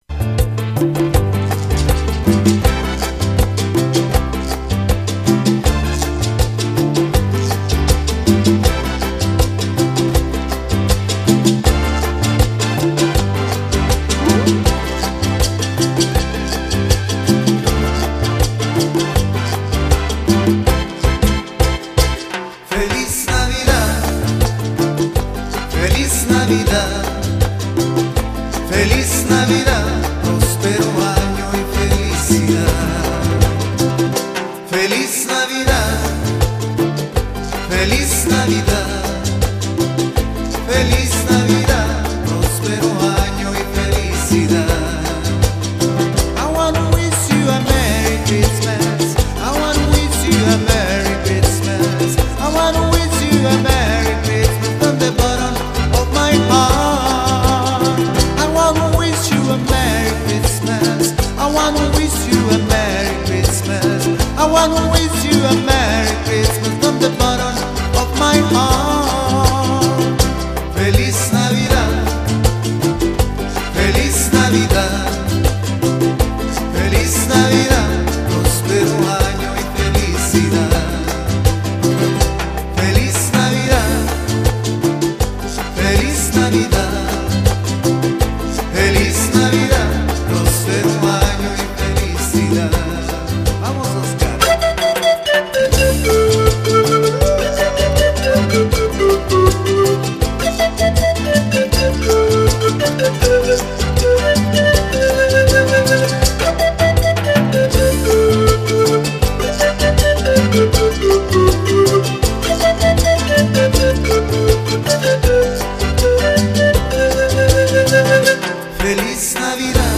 Sången blandas
med panflöjt, gitarr och andra instrument.